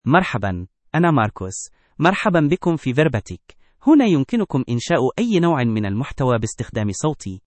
Marcus — Male Arabic (Standard) AI Voice | TTS, Voice Cloning & Video | Verbatik AI
MarcusMale Arabic AI voice
Marcus is a male AI voice for Arabic (Standard).
Voice sample
Listen to Marcus's male Arabic voice.
Marcus delivers clear pronunciation with authentic Standard Arabic intonation, making your content sound professionally produced.